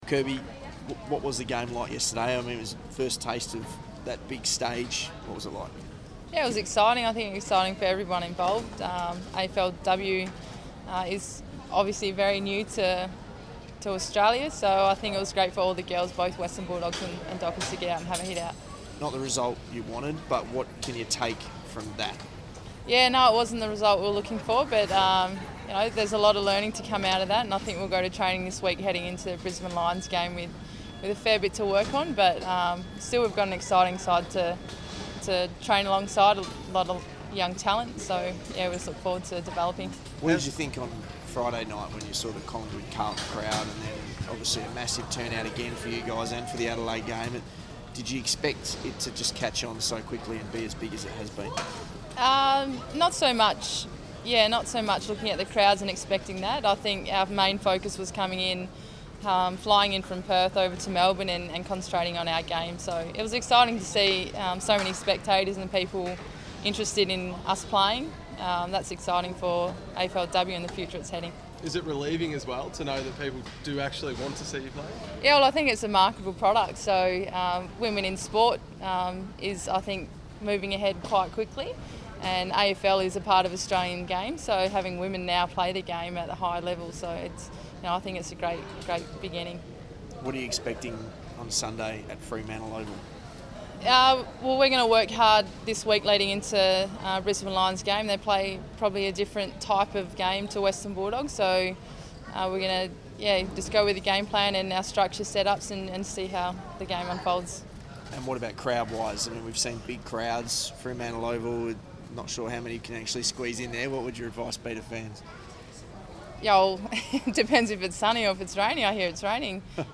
Media Conference